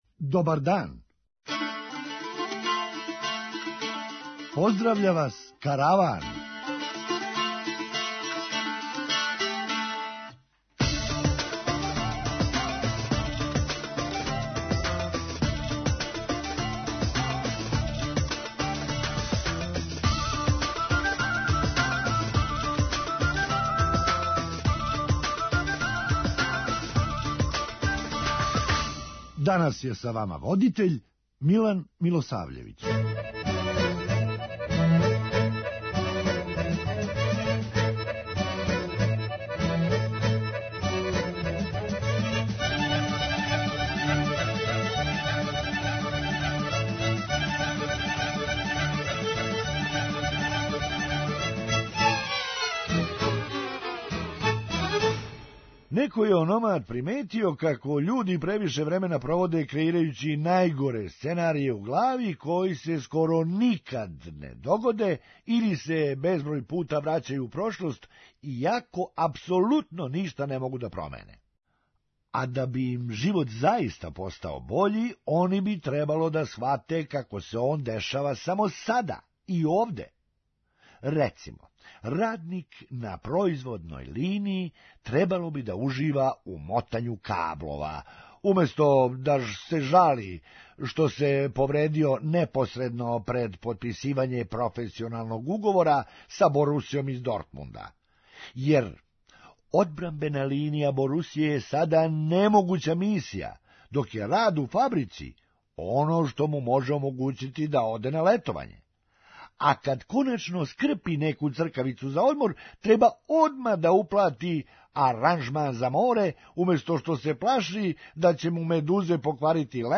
Хумористичка емисија
Али није искључио могућност да у Босни има фараона. преузми : 8.87 MB Караван Autor: Забавна редакција Радио Бeограда 1 Караван се креће ка својој дестинацији већ више од 50 година, увек добро натоварен актуелним хумором и изворним народним песмама.